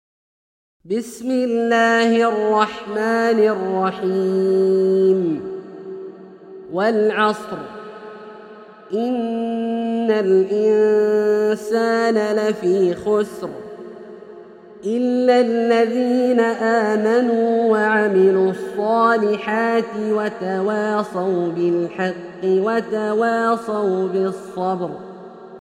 سورة العصر - برواية الدوري عن أبي عمرو البصري > مصحف برواية الدوري عن أبي عمرو البصري > المصحف - تلاوات عبدالله الجهني